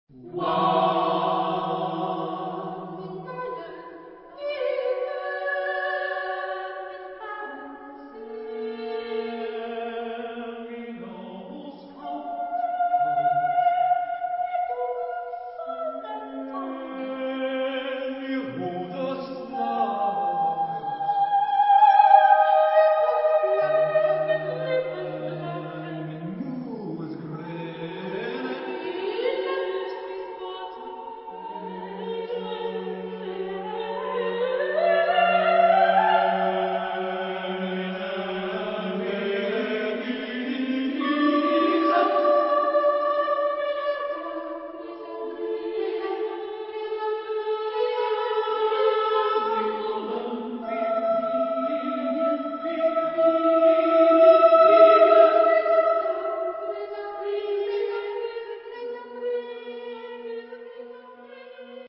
eight medieval lyrics for unaccompanied voices
Genre-Style-Forme : Profane ; Chœur
Type de choeur : SSATB  (5 voix mixtes )
Tonalité : mi mineur